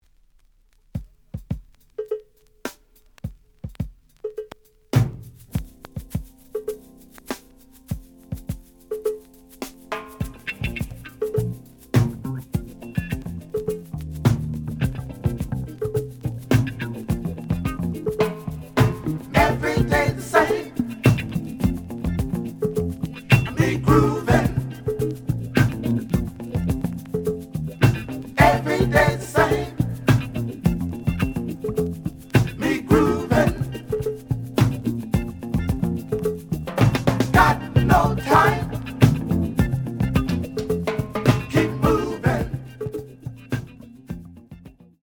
The audio sample is recorded from the actual item.
●Genre: Jazz Funk / Soul Jazz
Looks good, but slight noise on both sides.)